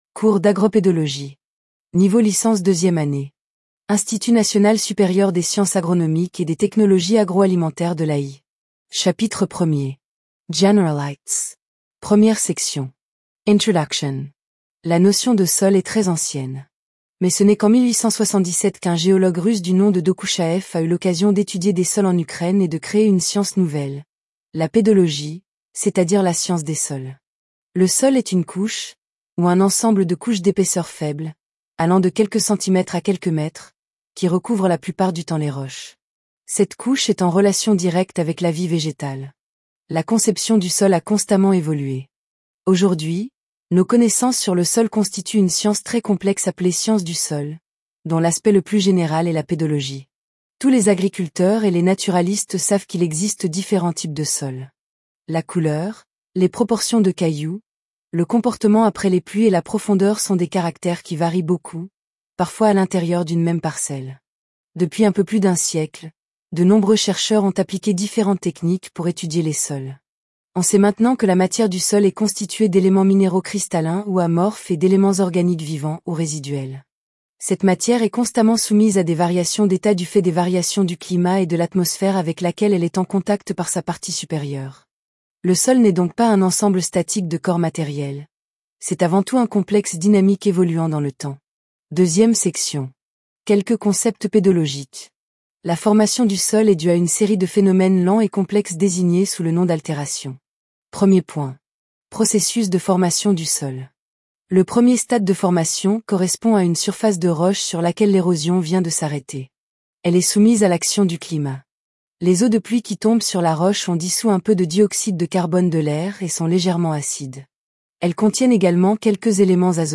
Podcast : Agro-Pedologie L2 Tts
Agro-pedologie_L2_TTS.mp3